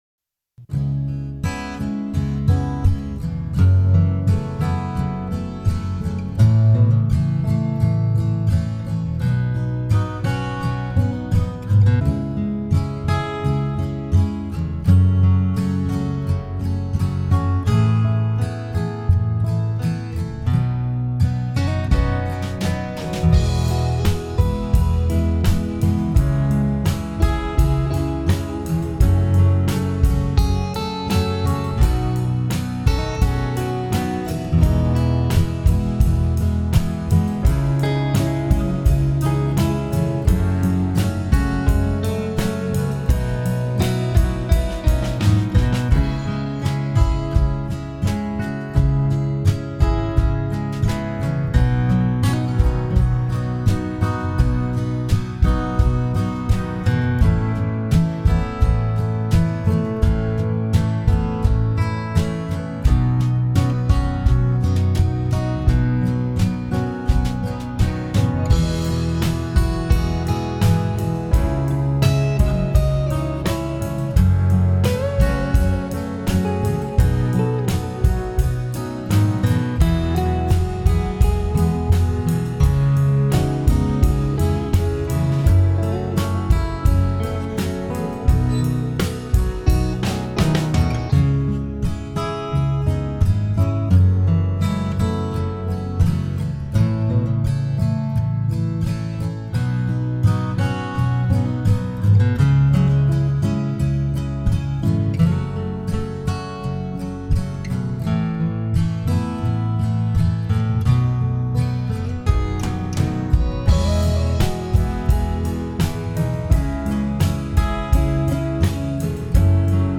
Home > Music > Blues > Smooth > Medium > Laid Back